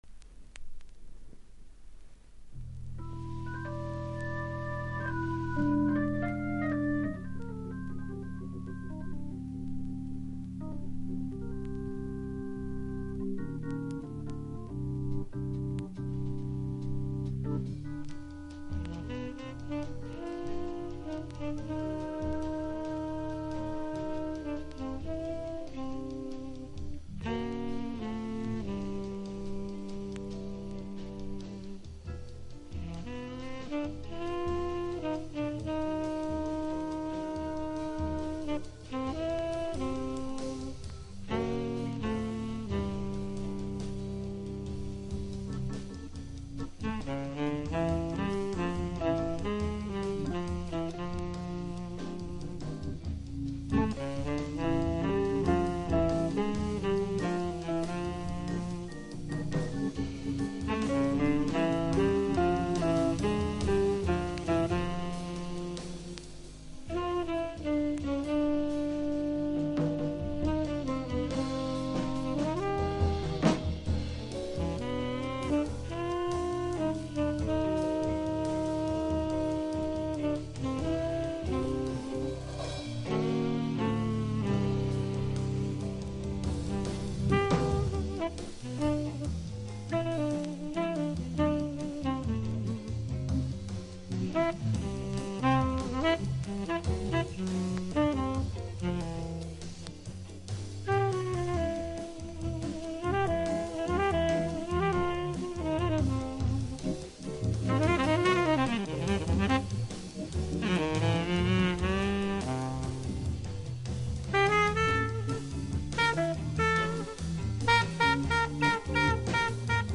ワンホーンものLive好内容盤